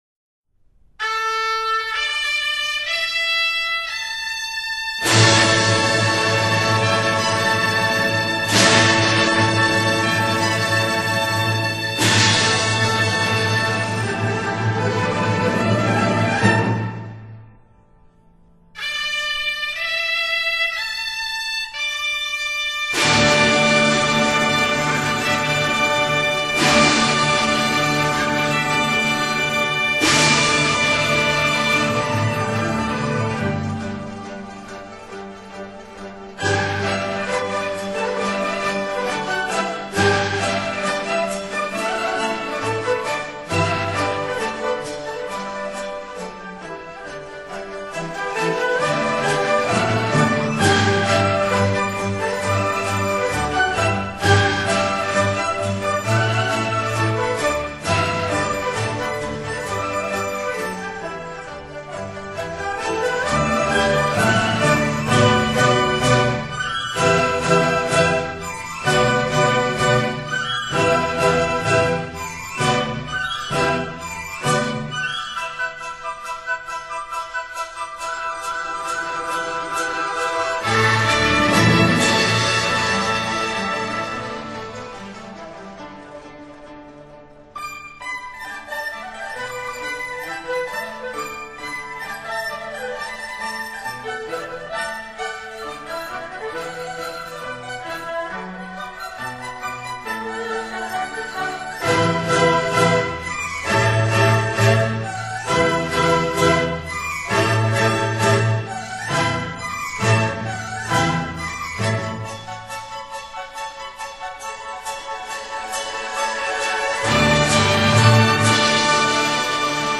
音乐类型: 中国国乐
虽然只用三只话筒，但整张唱片清晰、明亮，又极具动态，实在是近年来难得听见的民乐合奏精品！
全新的录音不但音响强劲，且具有非常逼人的豪迈气势。